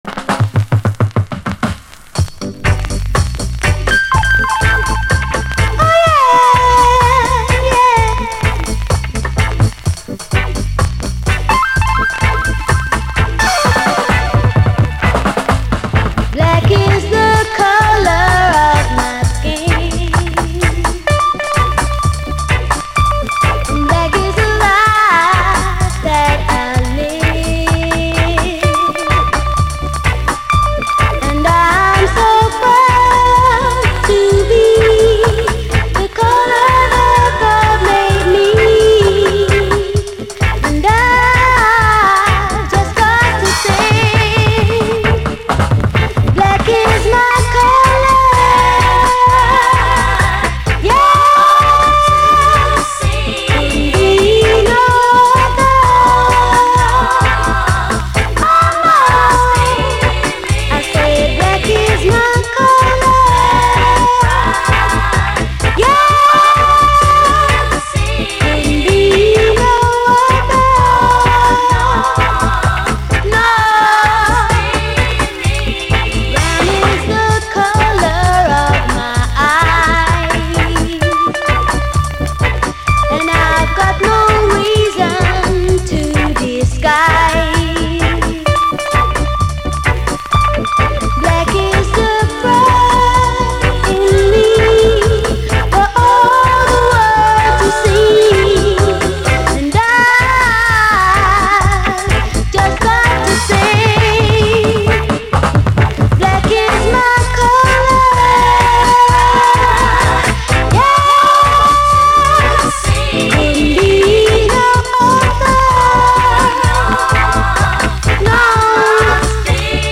REGGAE, 7INCH
黄金の77年初期UKラヴァーズ45！
試聴ファイルはこの盤からの録音です
ヴォーカル＆コーラスの儚い響きはマジで幻のようです。
」は、ピアノの響きが物悲しくてこちらも最高です。